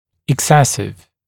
[ɪk’sesɪv] [ek-][ик’сэсив] [эк-]чрезмерный, избыточный